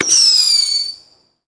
信号弹.mp3